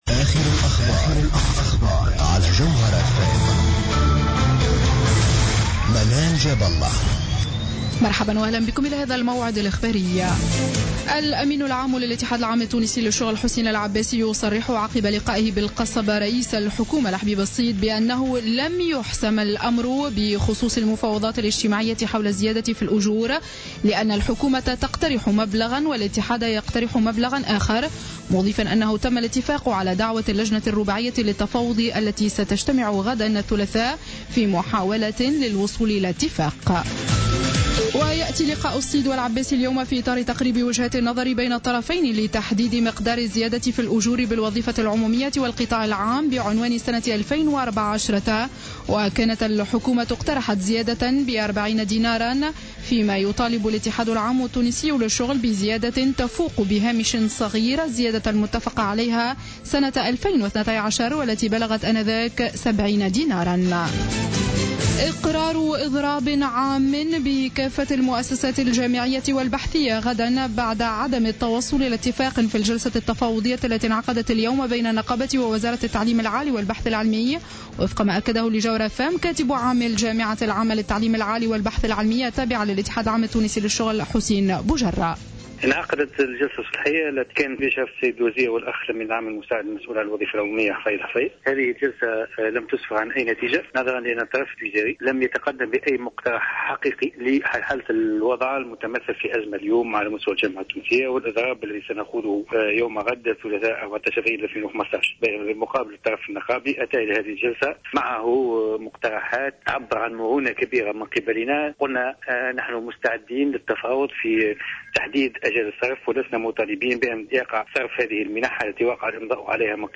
نشرة أخبار السابعة مساء ليوم الاثنين 13 أفريل 2015